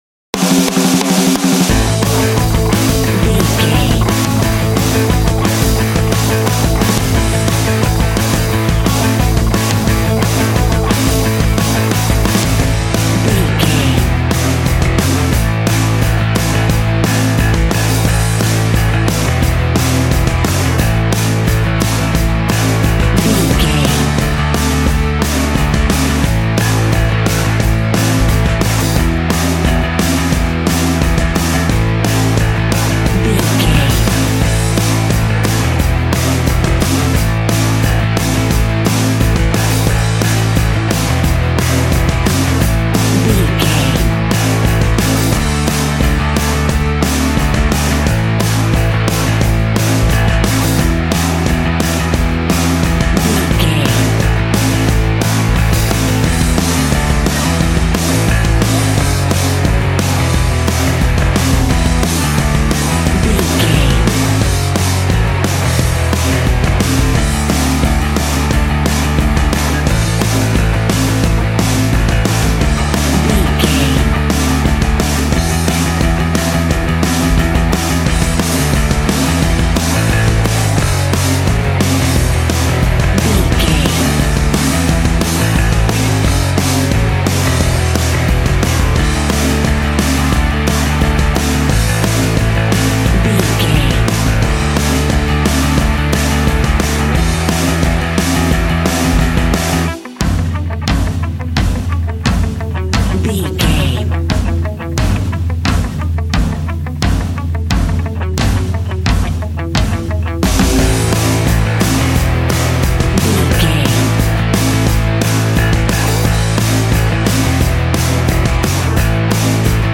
Ionian/Major
groovy
powerful
electric organ
drums
electric guitar
bass guitar